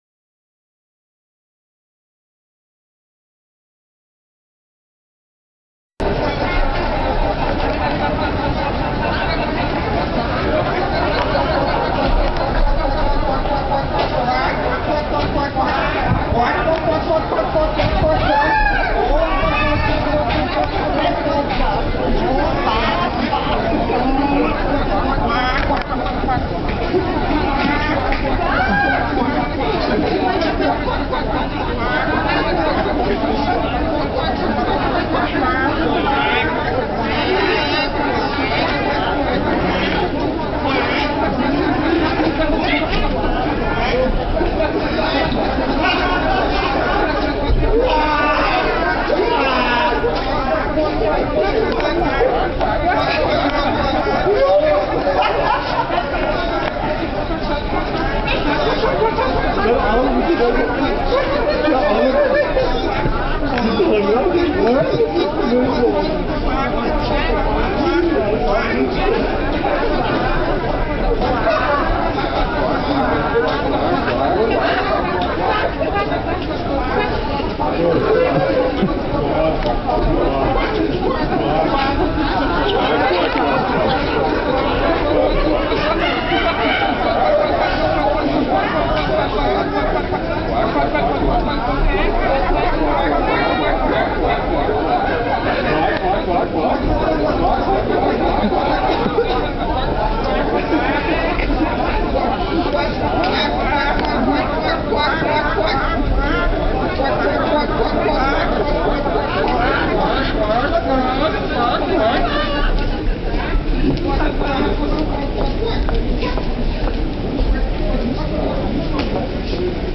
So luden wir einige Freunde zum ersten Wiener Entmob oder Quakmob oder Flashmob oder Gelbmob... gans egal, jedenfalls also trafen wir uns mit einigen Leuten in einer U-Bahn-Station, um kräftig gemeinsam zu quaken.